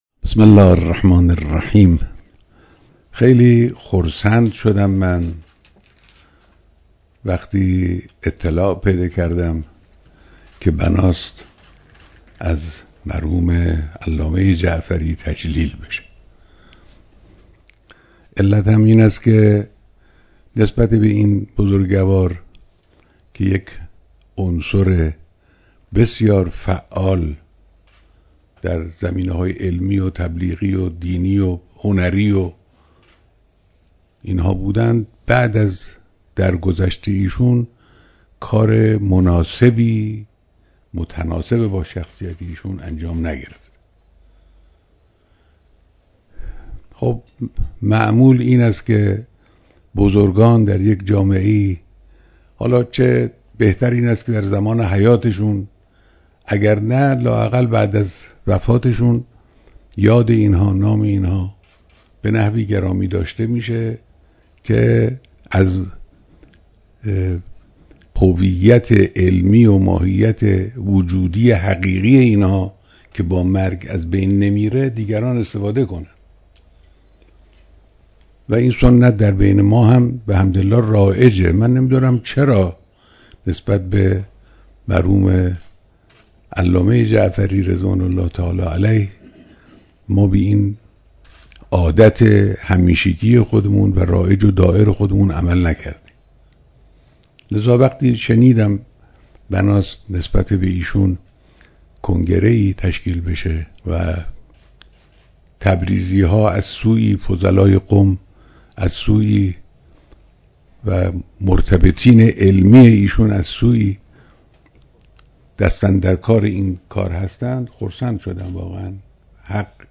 بیانات در دیدار اعضای ستاد نکوداشت علامه محمدتقی جعفری